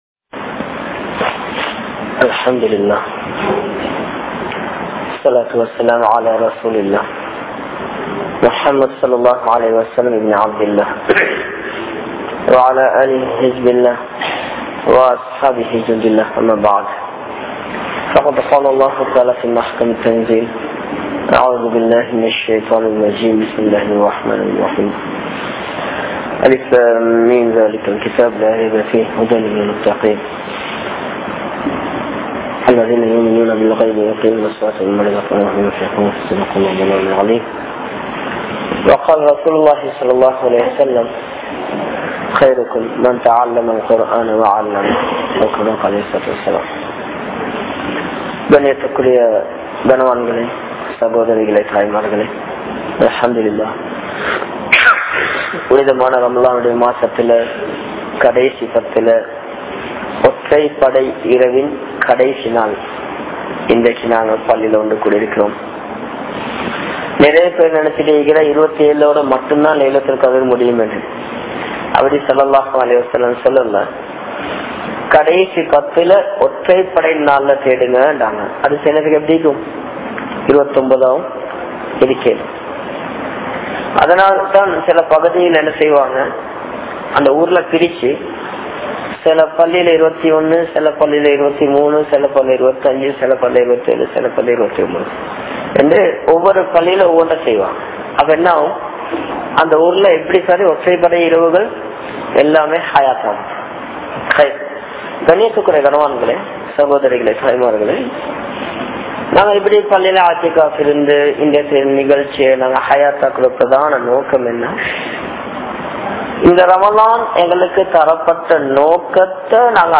Paavangal(Sins) | Audio Bayans | All Ceylon Muslim Youth Community | Addalaichenai